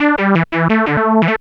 Synth 27.wav